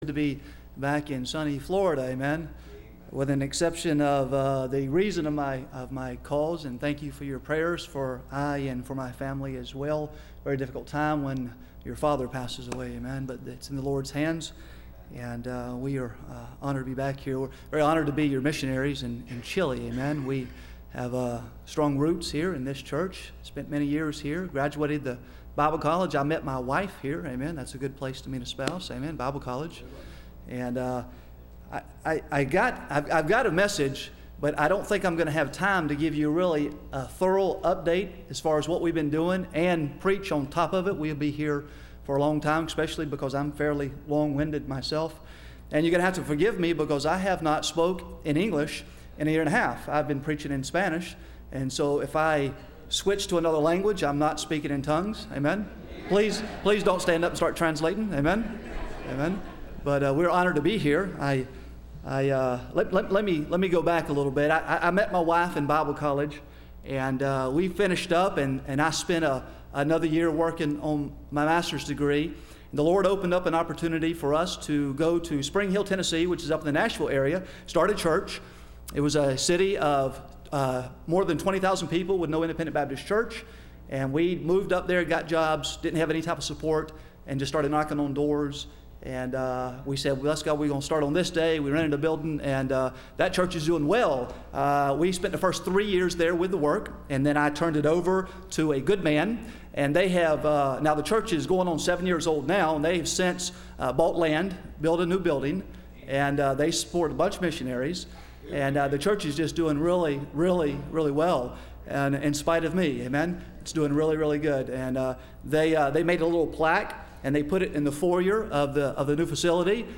Testimony / Report – Landmark Baptist Church
Service Type: Sunday Evening